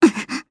Gremory-Vox_Damage_jp_01.wav